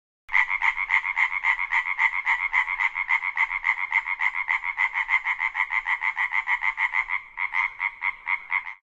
Free Nature sound effect: Single Frog Fast Croaks.
Single Frog Fast Croaks
Single Frog Fast Croaks.mp3